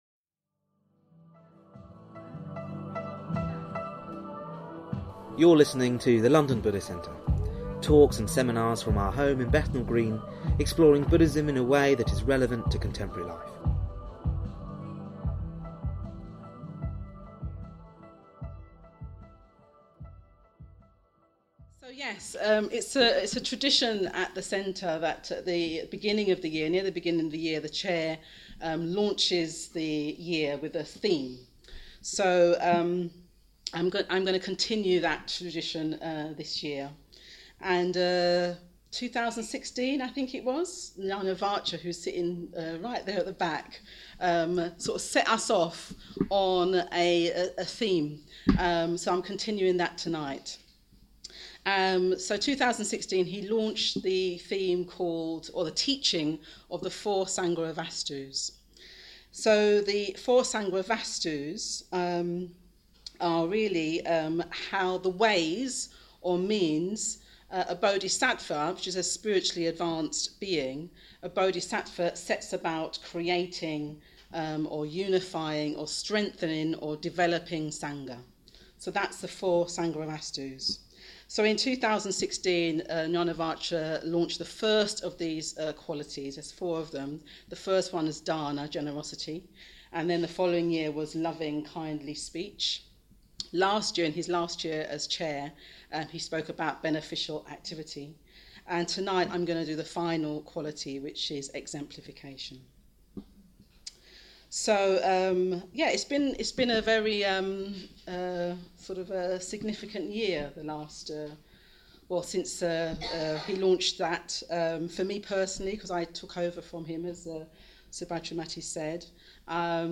Societies are, on the one hand, fragmenting whilst, on the other, unifying within a growing culture of consumerism and individualism. How can we create a community that is unified and diverse, exemplifying real values and being a force for good in the world? A keynote talk